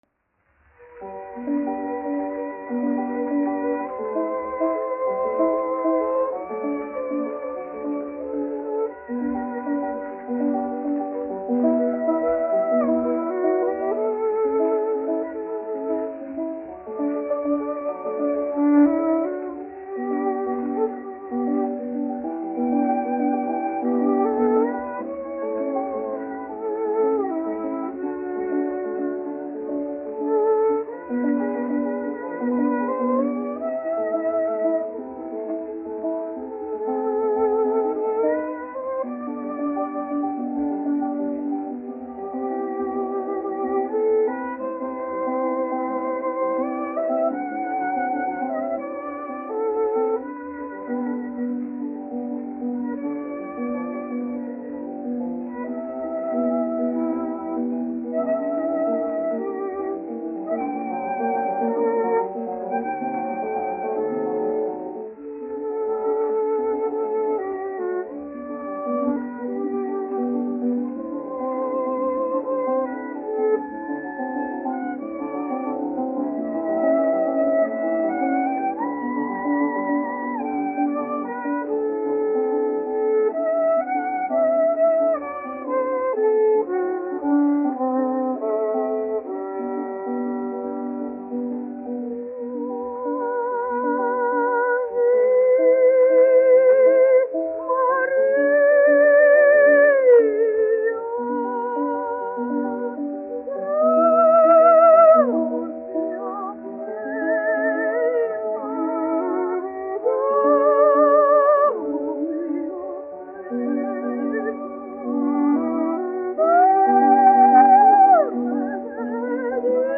1 skpl. : analogs, 78 apgr/min, mono ; 25 cm
Dziesmas (augsta balss)
Garīgās dziesmas
Latvijas vēsturiskie šellaka skaņuplašu ieraksti (Kolekcija)